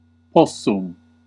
Ääntäminen
France (Paris): IPA: [pu.vwaʁ]